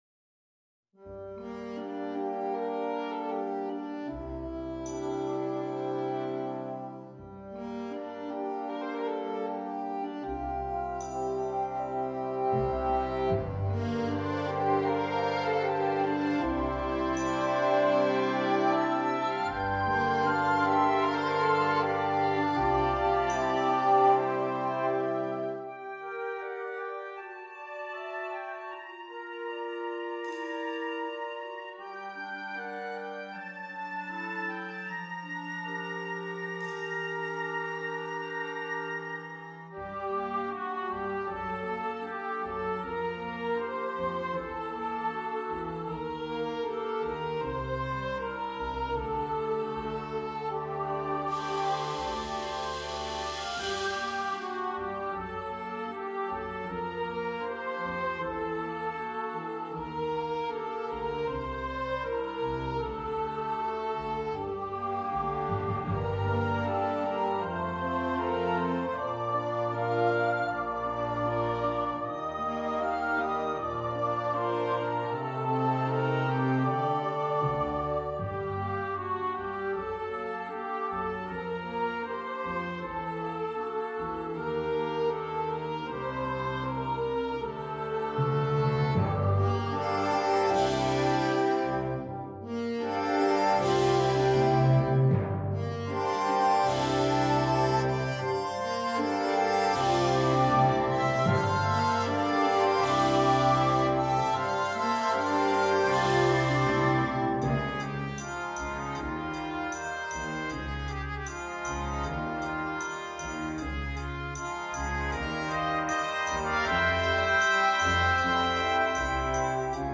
Besetzung: Children Choir & Concert Band